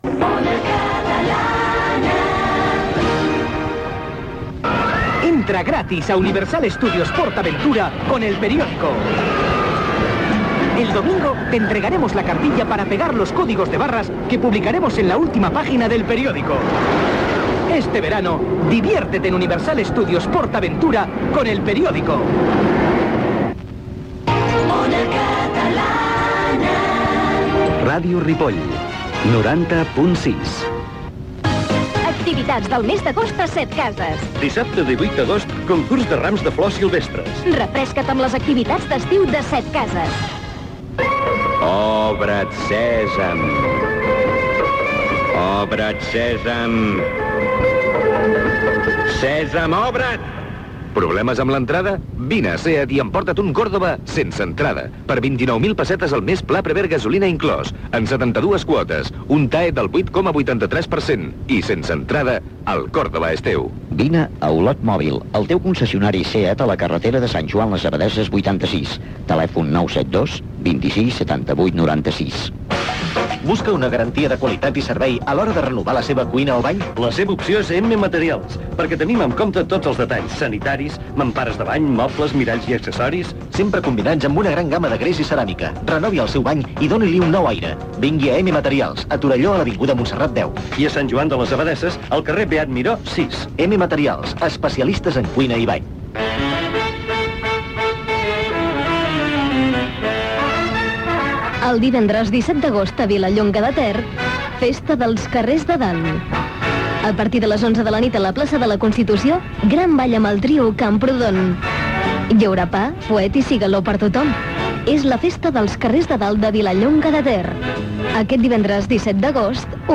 Indicatiu de la cadena, publicitat, identificació de l'emissora, publicitat, identificació de l'emissora.
FM